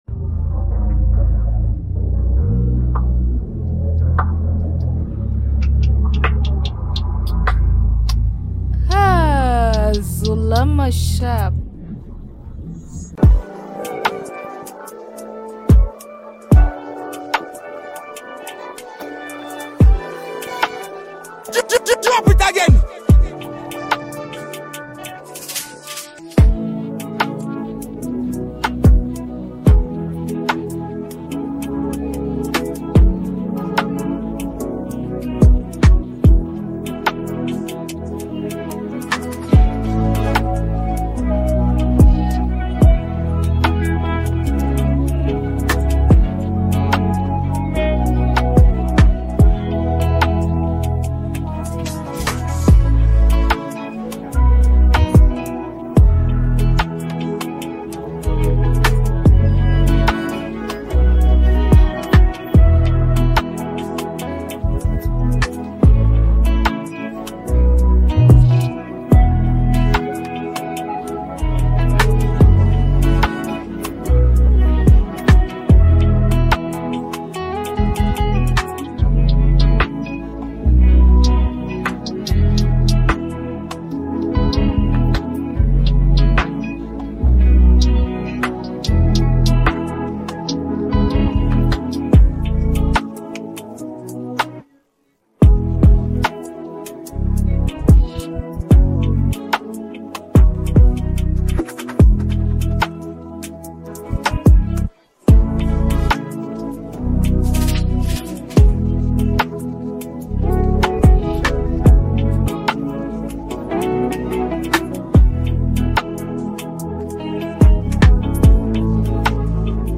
Afrobeats hiphop trap beats